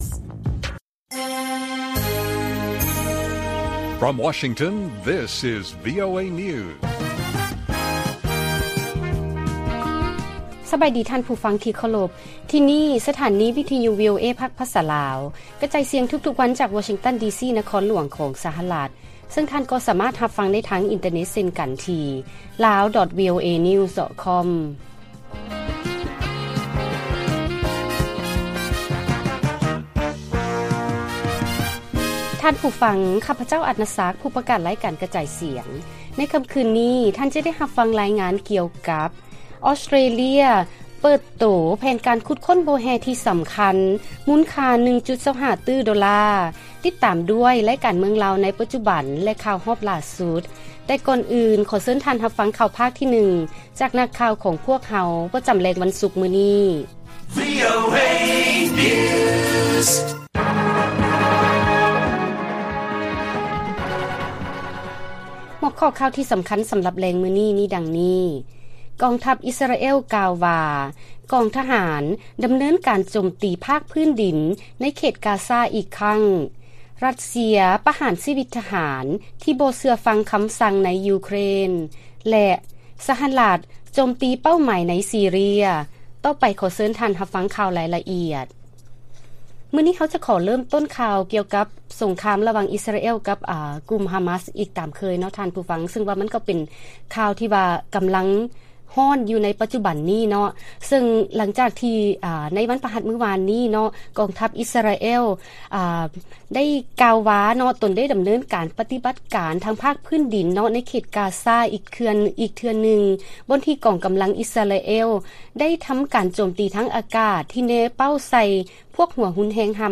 ວີໂອເອພາກພາສາລາວ ກະຈາຍສຽງທຸກໆວັນ, ຫົວຂໍ້ຂ່າວທີ່ສຳຄັນສຳລັບມື້ນີ້ມີດັ່ງນີ້: 1. ກອງທັບອິສຣາແອລ ກ່າວວ່າ ກອງທະຫານ ດຳເນີນການໂຈມຕີພາກພື້ນດິນ ໃນເຂດກາຊາ ອີກຄັ້ງ, 2. ຣັດເຊຍ ປະຫານຊີວິດທະຫານທີ່ບໍ່ເຊື່ອຟັງຄຳສັ່ງໃນ ຢູເຄຣນ, ແລະ 3. ສະຫະລັດ ໂຈມຕີເປົ້າໝາຍໃນ ຊີເຣຍ.